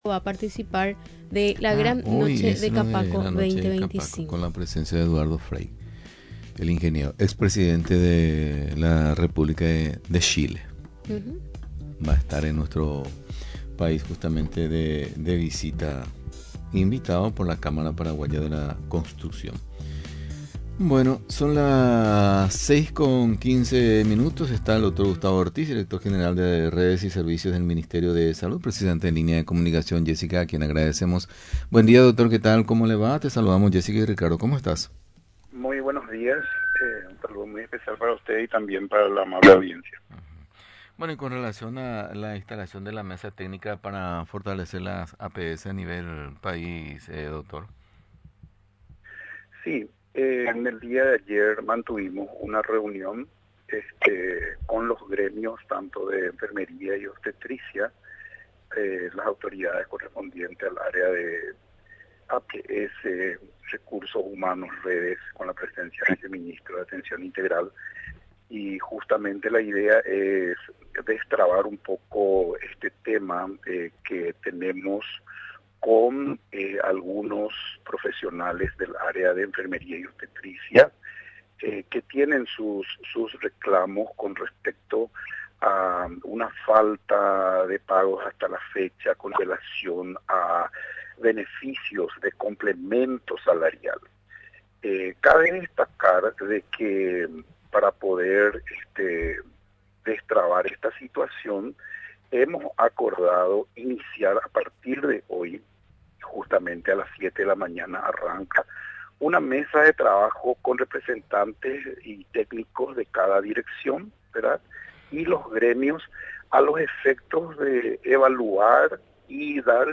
El Dr. Gustavo Ortiz, Director General de Redes y Servicios del Ministerio de Salud Pública y Bienestar Social, conversó con Radio Nacional del Paraguay acerca de la instalación de una mesa técnica que busca fortalecer la Atención Primaria de la Salud (APS).